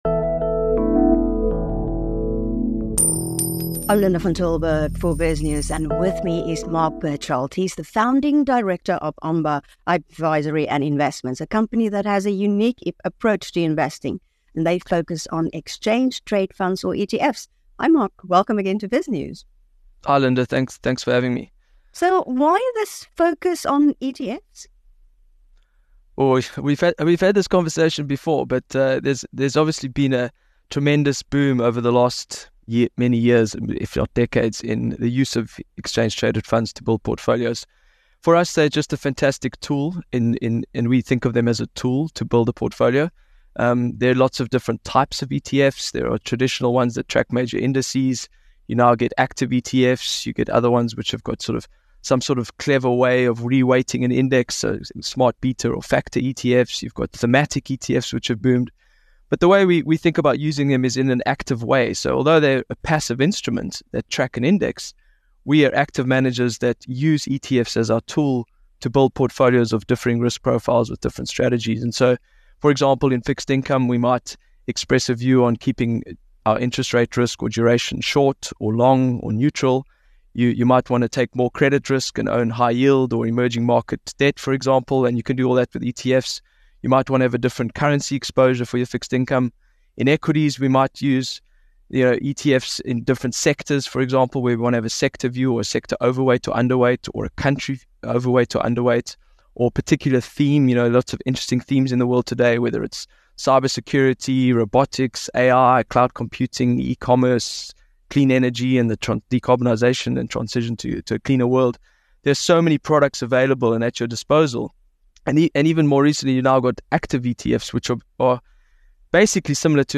In an interview with BizNews